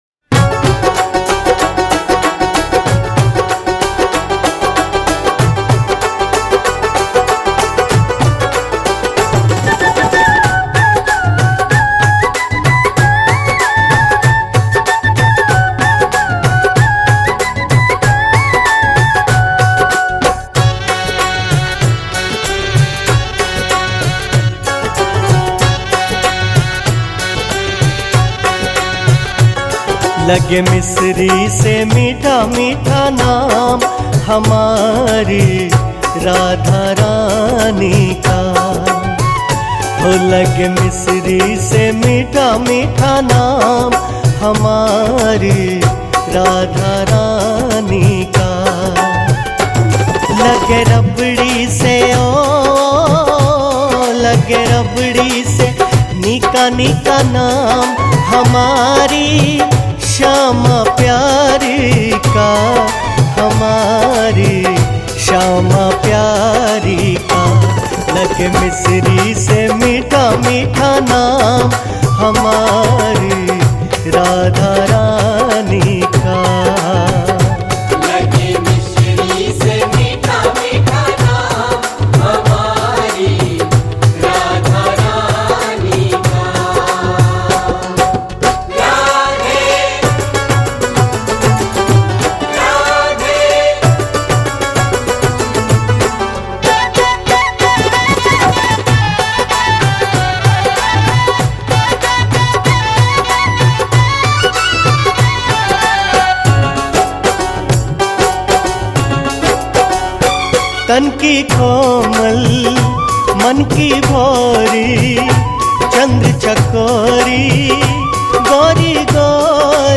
Radha Bhajan | Krishna Bhajan
Hindi Bhajan